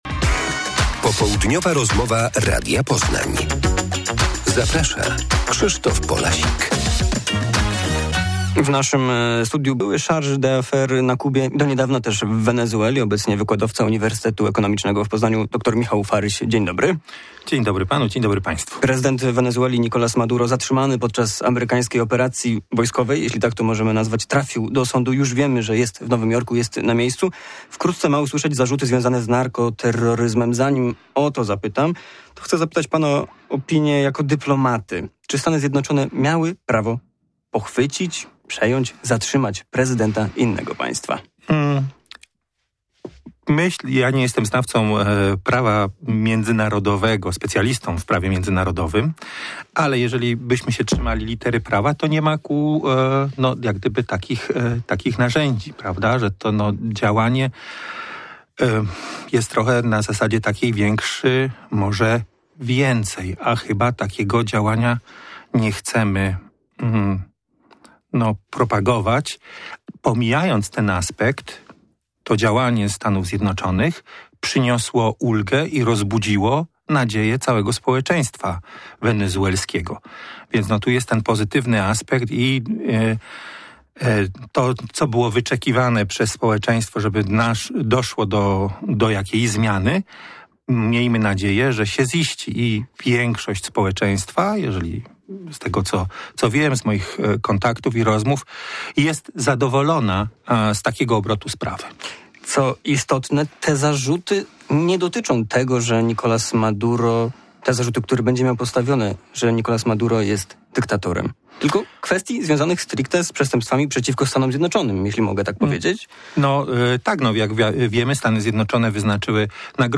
Prezydent Wenezueli i jego żona mają dziś stanąć przed sądem w Stanach Zjednoczonych. O sytuacji w kraju po amerykańskiej interwencji mówi gość programu